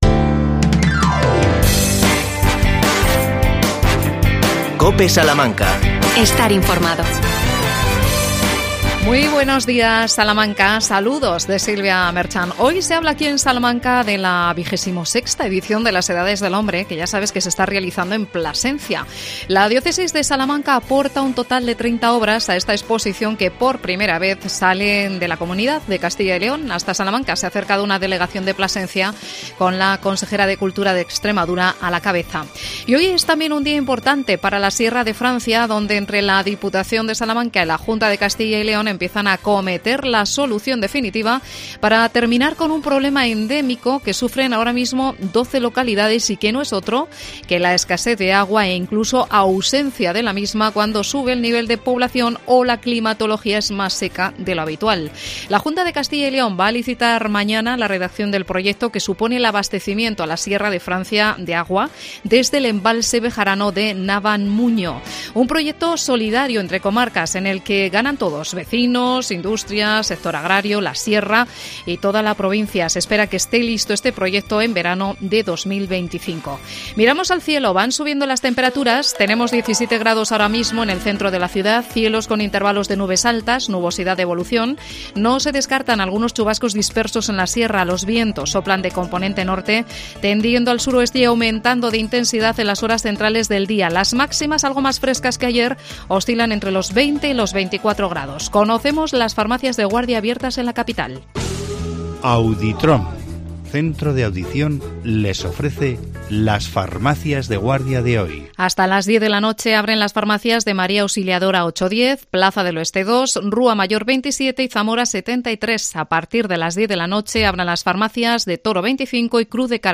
AUDIO: Entrevista a Jaime Mayor Oreja. Presentación de la plataforma NEOS este jueves días 2 de junio, en Salamanca.